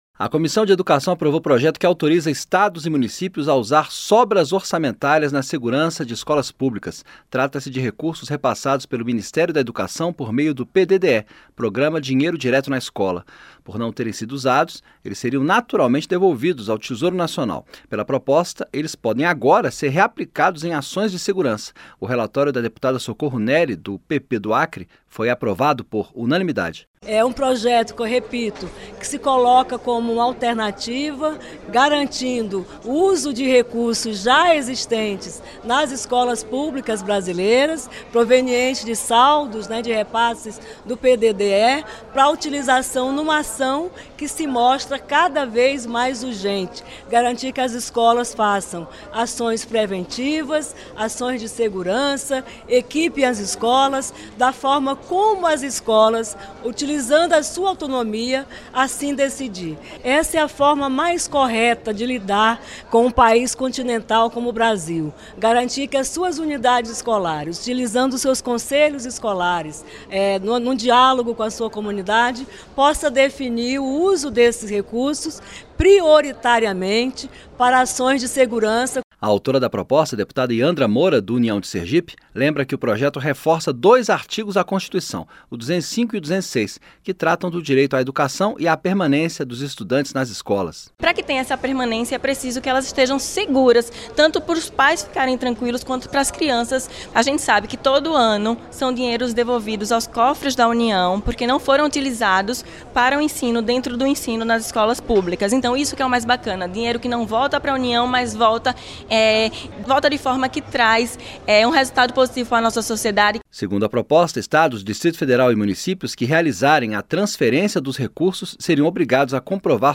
PROPOSTA APROVADA EM COMISSÃO DA CÂMARA PERMITE QUE RECURSOS ORÇAMENTÁRIOS DA ÁREA DE EDUCAÇÃO POSSAM SER USADOS PARA GARANTIR SEGURANÇA NAS ESCOLAS. A REPORTAGEM